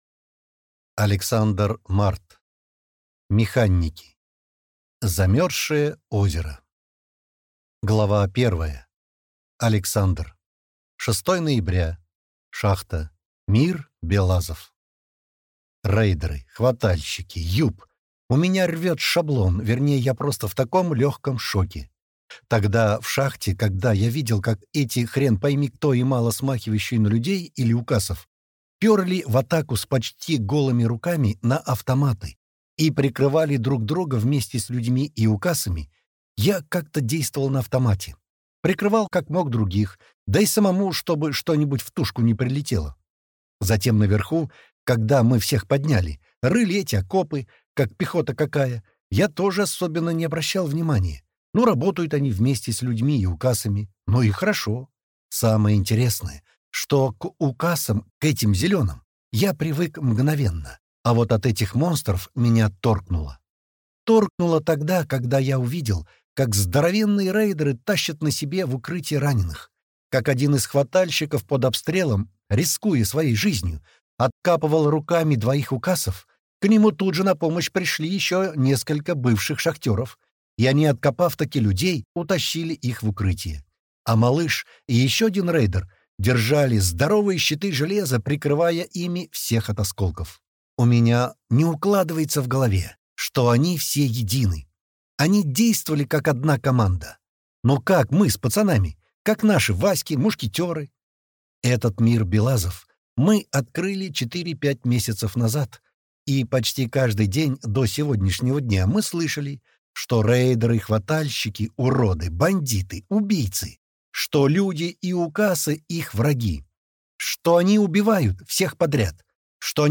Аудиокнига Механики. Замерзшее озеро | Библиотека аудиокниг